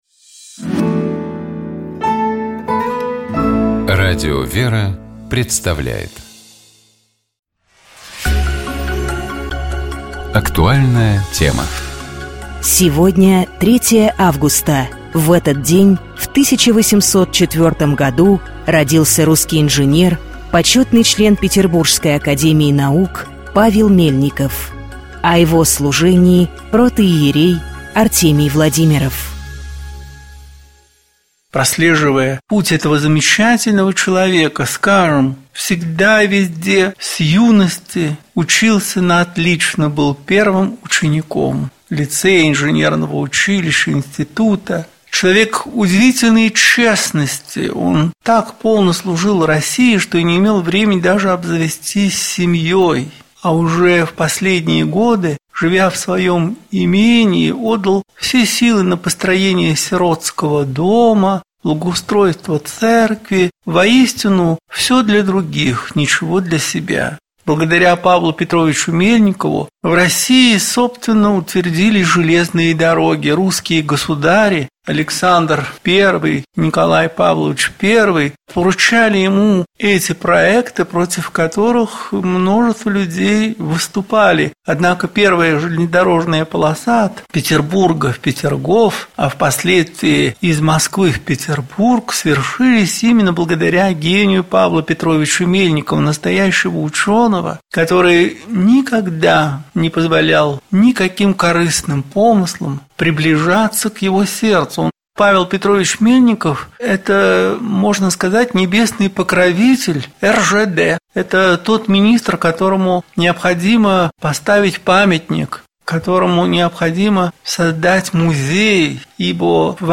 протоиерей